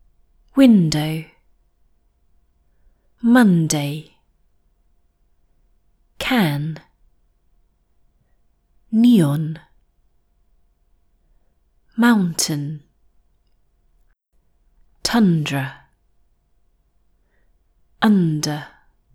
Instead of lowering the tip of your tongue when you pronounce “n”, try positioning the tip of the tongue on the bump behind your front teeth.